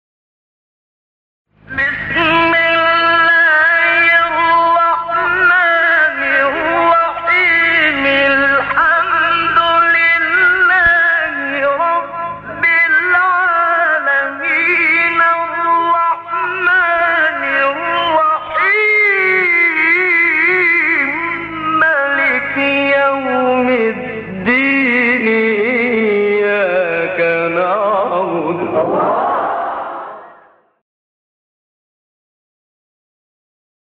سایت قرآن کلام نورانی - سه گاه منشاوی (2).mp3